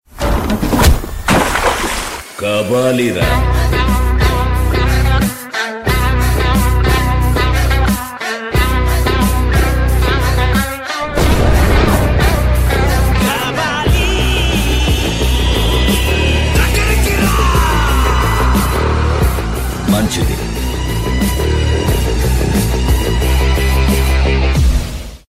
Bollywood RingTones , Electronica Ringtones